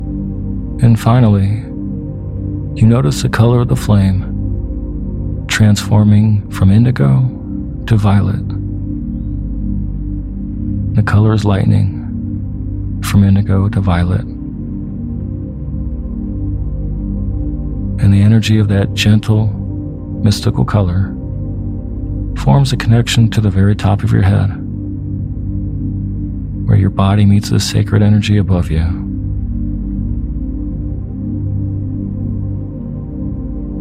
Guided Meditation For Manifesting Your Desires With Chakra Balancing (Version 2.0)